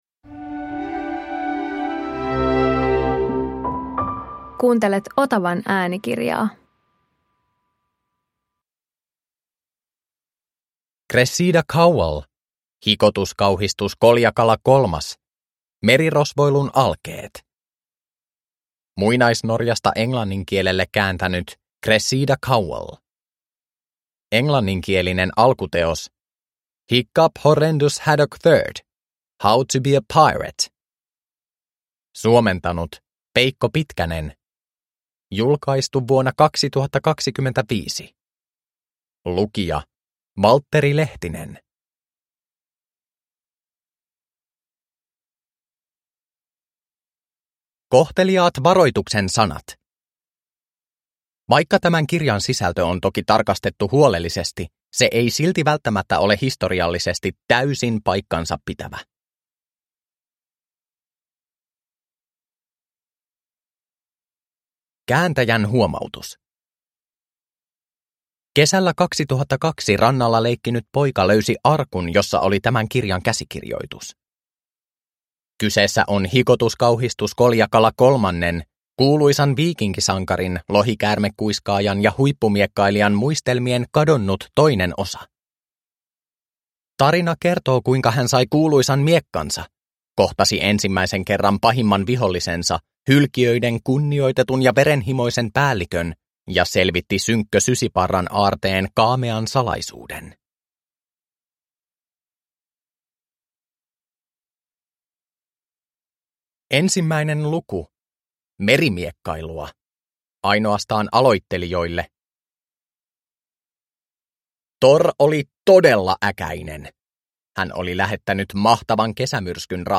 Merirosvoilun alkeet – Ljudbok